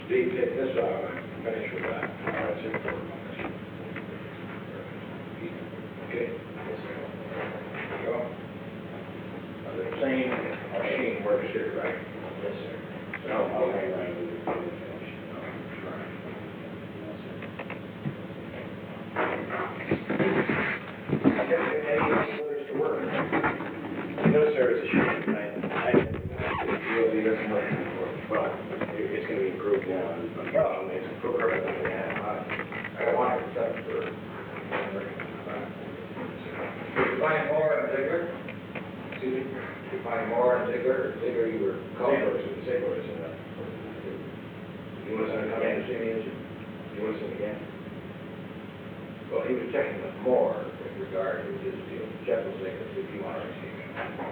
Secret White House Tapes
Conversation No. 442-58
Location: Executive Office Building